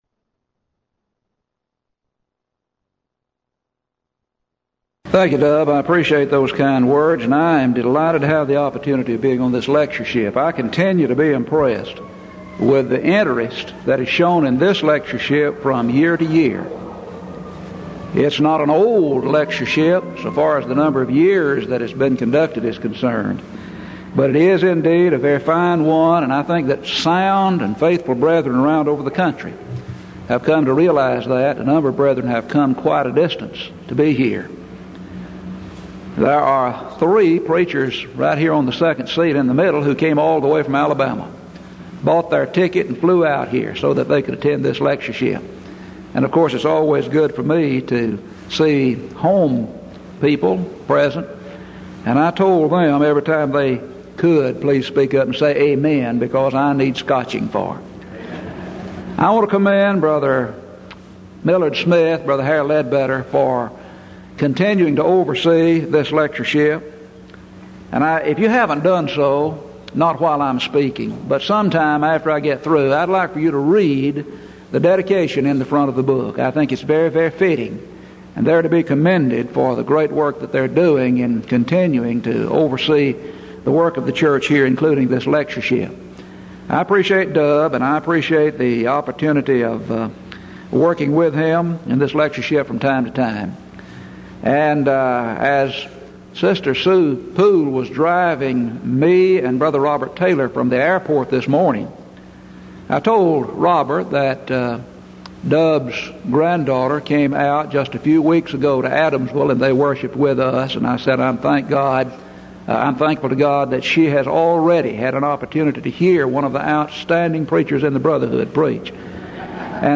Event: 1986 Denton Lectures
lecture